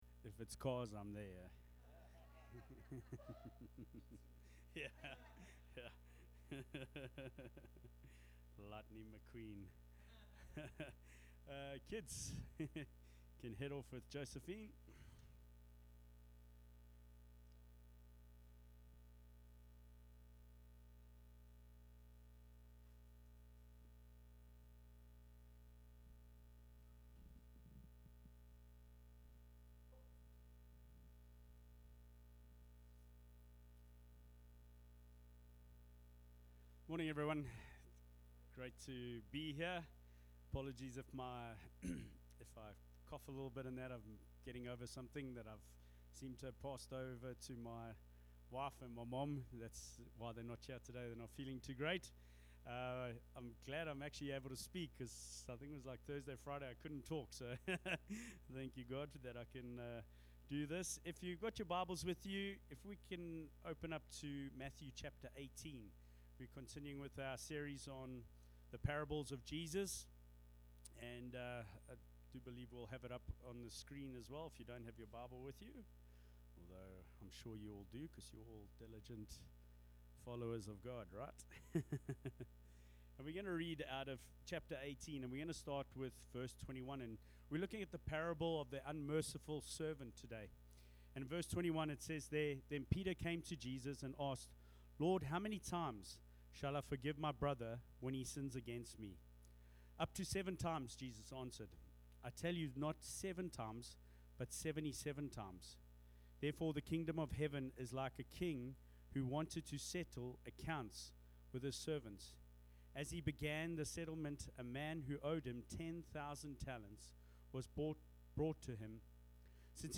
Sermons | Explore Life Church